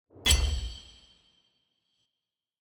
Sword block 2.wav